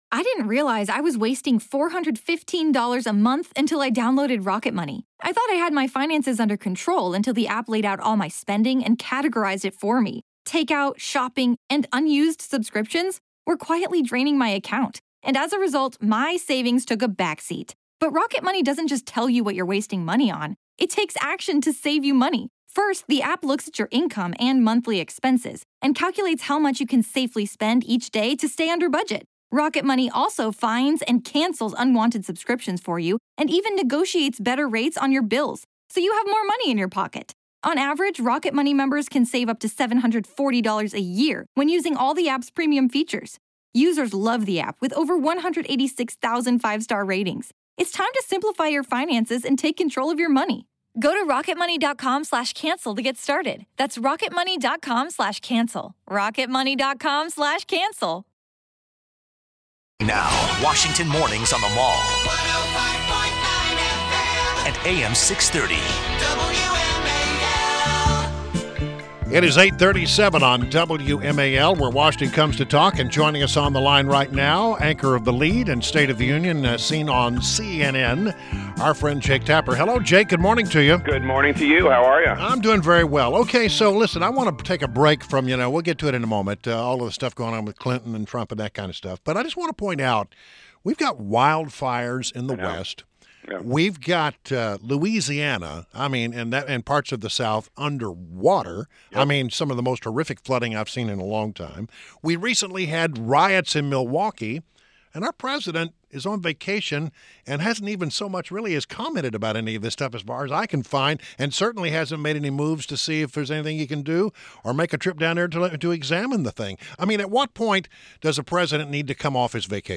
WMAL Interview - JAKE TAPPER - 08.18.16